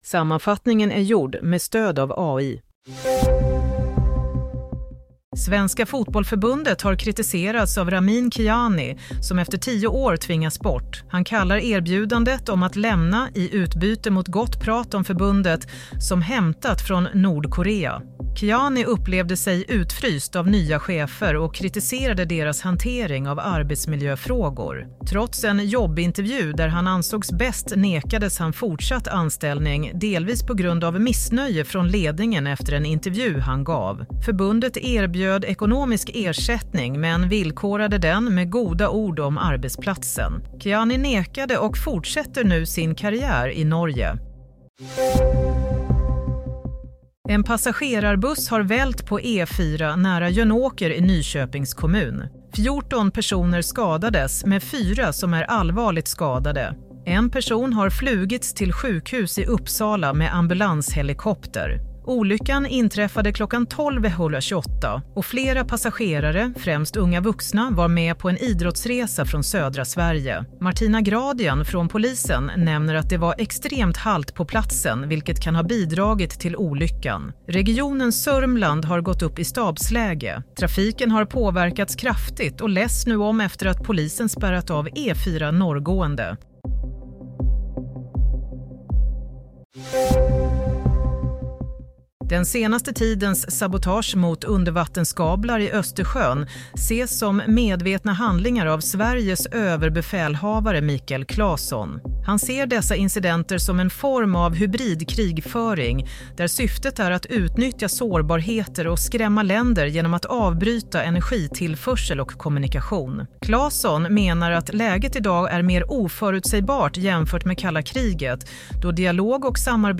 Nyhetssammanfattning – 11 januari 16:00